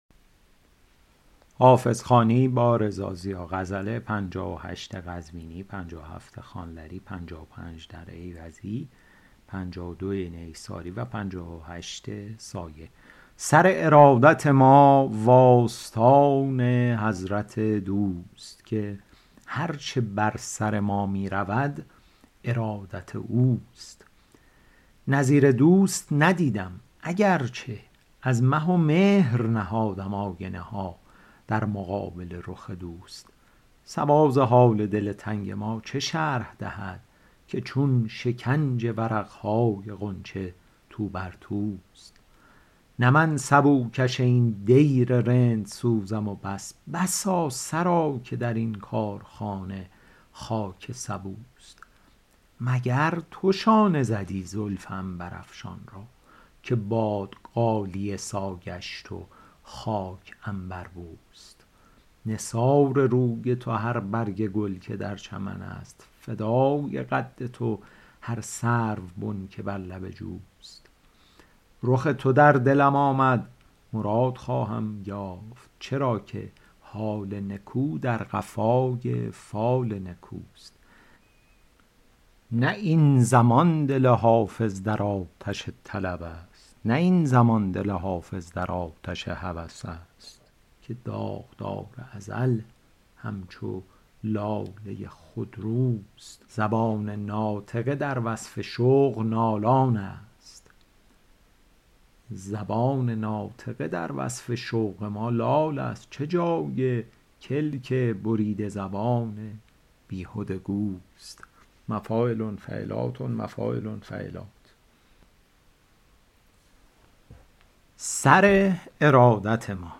شرح صوتی غزل شمارهٔ ۵۸